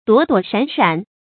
躲躲闪闪 duǒ duǒ shǎn shǎn 成语解释 躲避闪开，以免遇到某些情况。